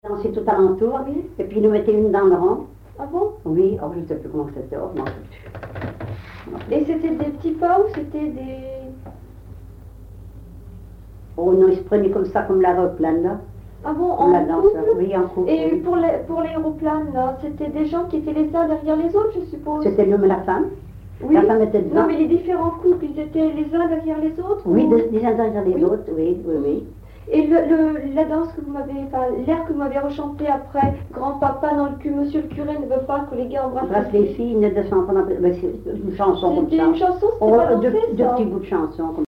Localisation Cancale (Plus d'informations sur Wikipedia)
Catégorie Témoignage